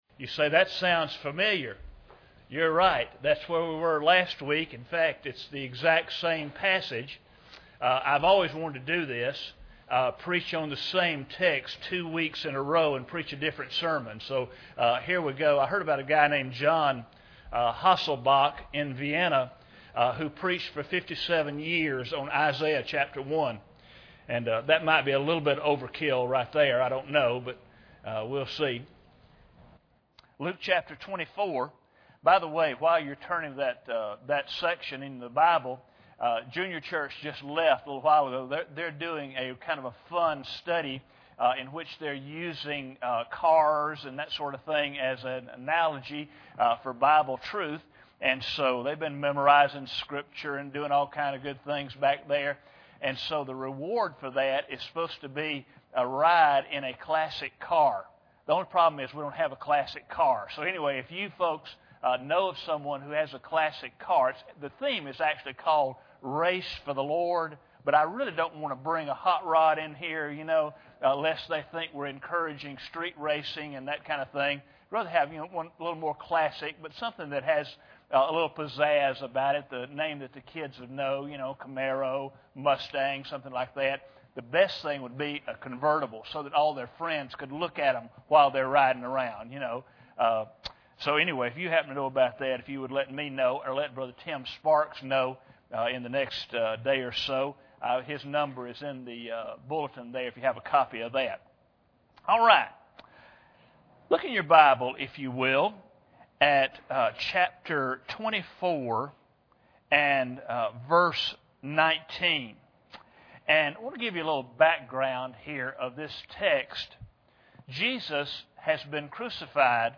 Passage: Luke 24:19-27 Service Type: Sunday Morning Bible Text